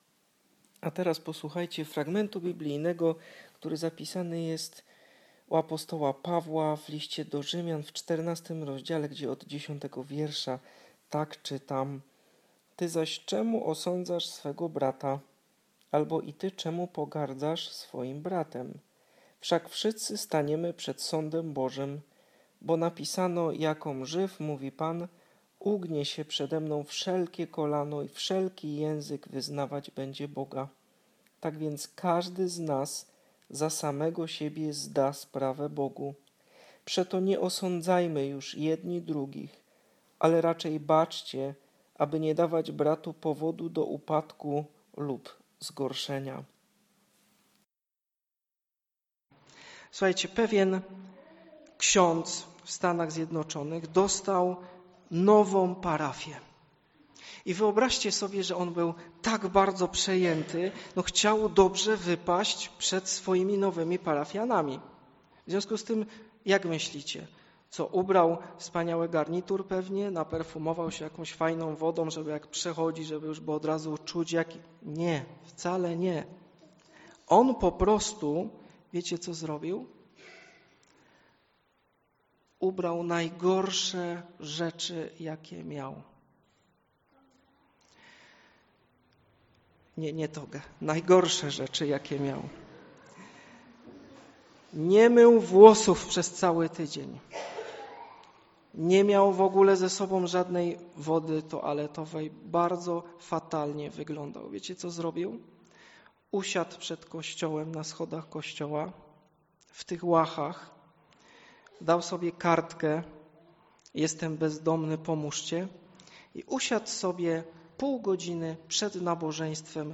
4. Niedziela po Trójcy Świętej – Rz 14,10-13 (fragment kazania)